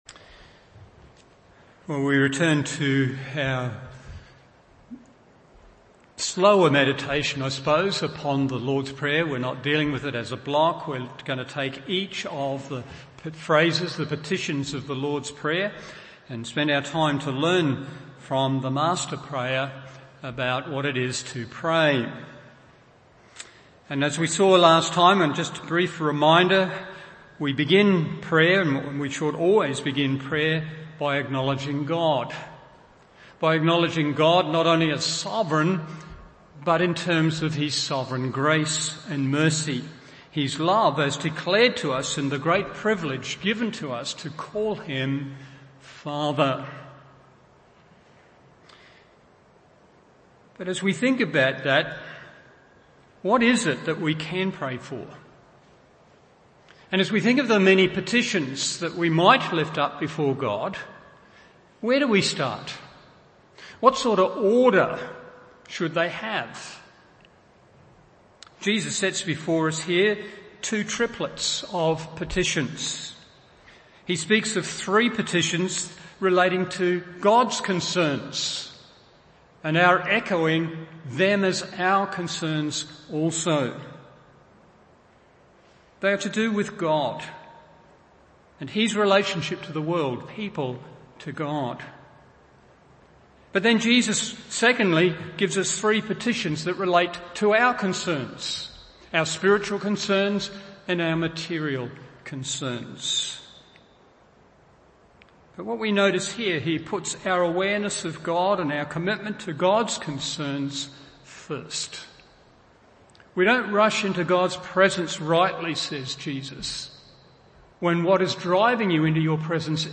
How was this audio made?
Morning Service Matt 6:9 1. What We Are Saying 2. What We Are Praying…